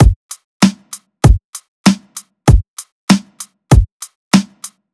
FK097BEAT1-R.wav